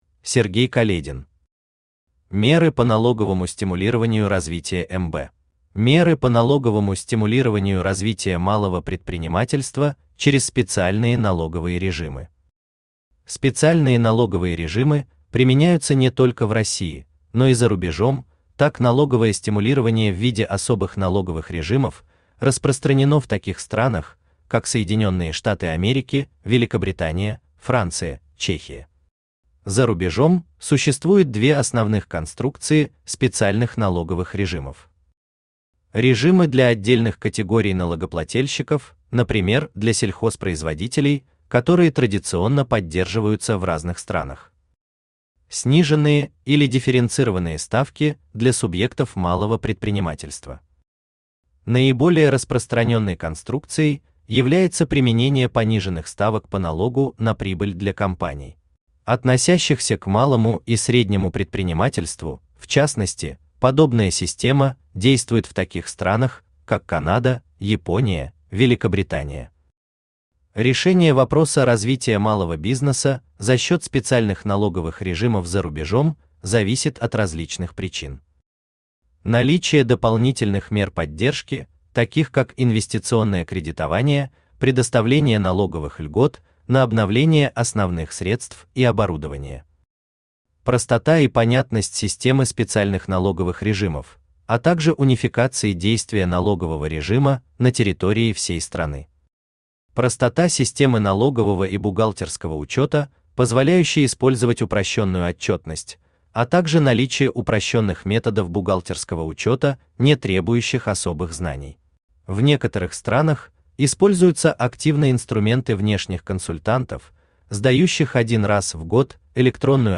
Аудиокнига Меры по налоговому стимулированию развития МБ | Библиотека аудиокниг
Aудиокнига Меры по налоговому стимулированию развития МБ Автор Сергей Каледин Читает аудиокнигу Авточтец ЛитРес.